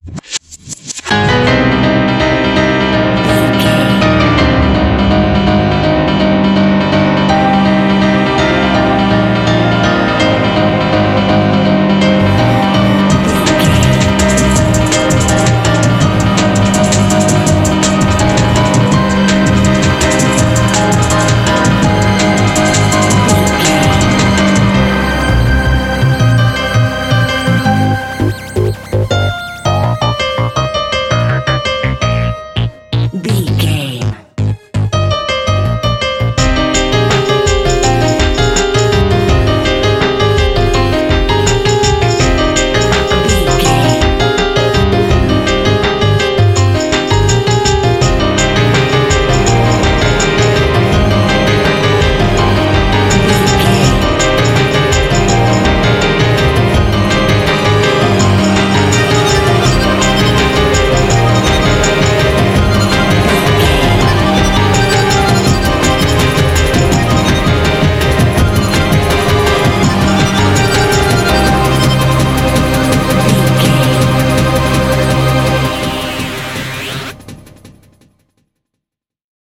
In-crescendo
Thriller
Aeolian/Minor
Fast
scary
ominous
suspense
eerie
piano
synthesiser
drums
bass guitar
pads